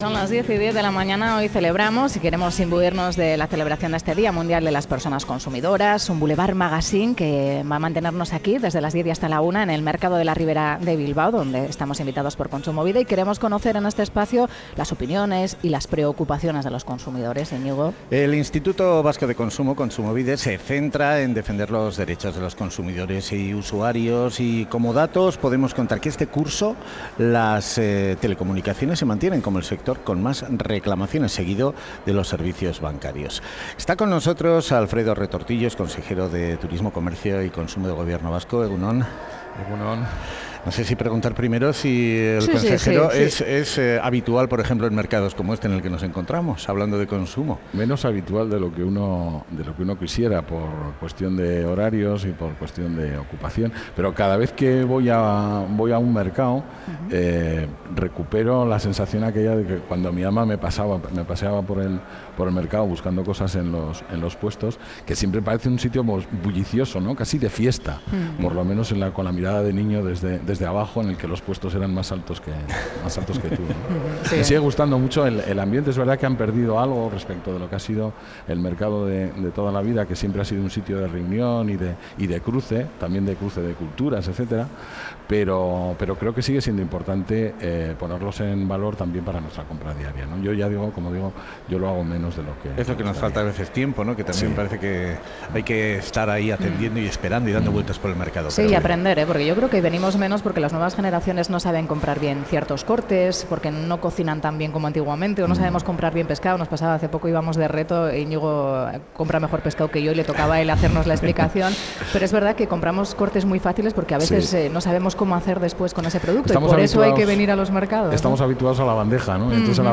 Audio: En el Día Mundial de las Personas Consumidoras Boulevard Magazine acude al Mercado de la Ribera de Bilbao. Charlamos con Alfredo Retortillo,Consejero de Turismo,Comercio y Consumo del Gobierno Vasco.